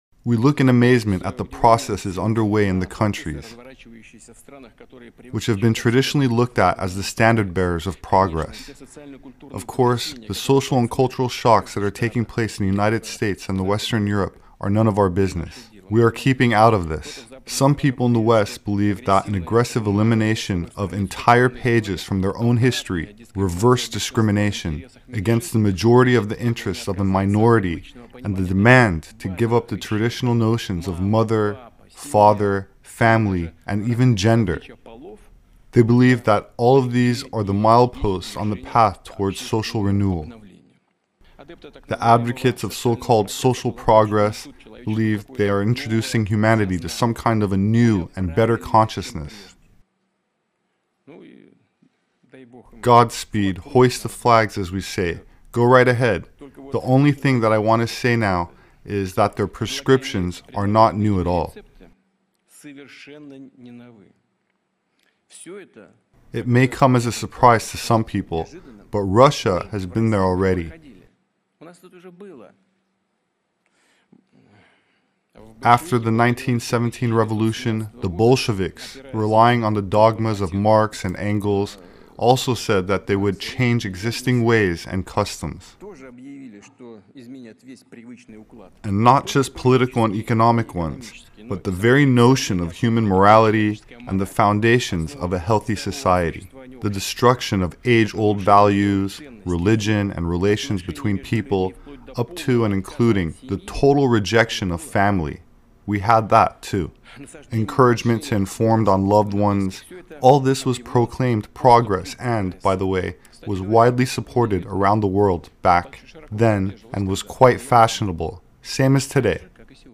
Putin delivers scathing speech at the West